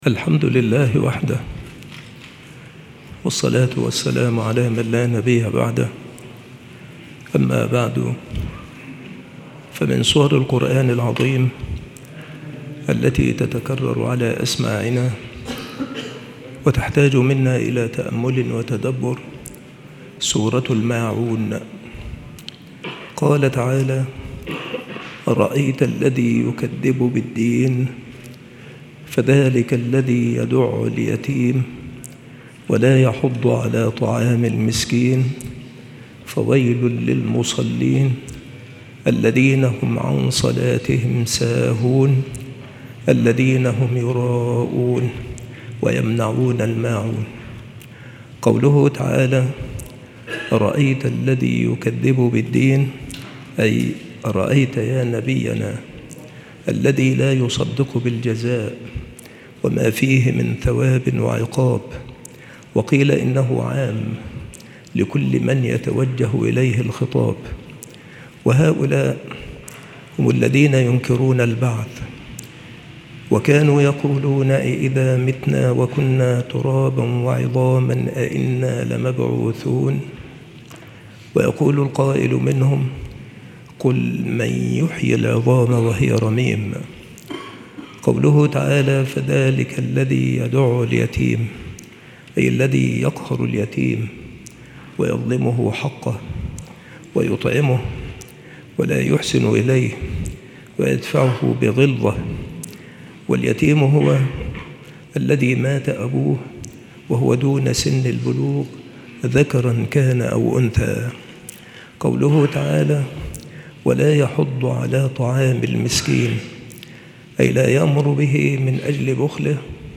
مواعظ وتذكير
مكان إلقاء هذه المحاضرة بالمسجد الشرقي - سبك الأحد - أشمون - محافظة المنوفية - مصر